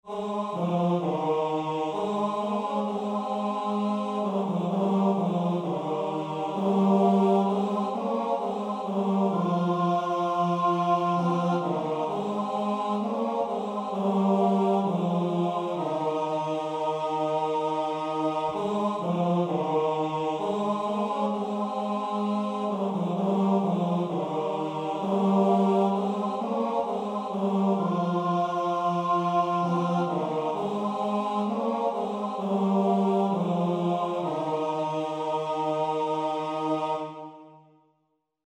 – Комп'ютерне відтворення нот: mp3.